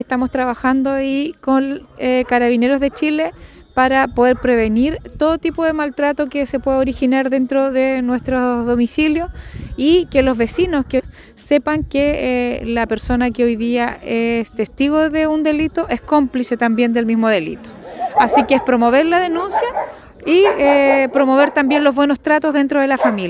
Por medio de cápsulas radiales, hacen un llamado a denunciar el maltrato hacia la mujer, el abuso infantil y el consumo excesivo de alcohol.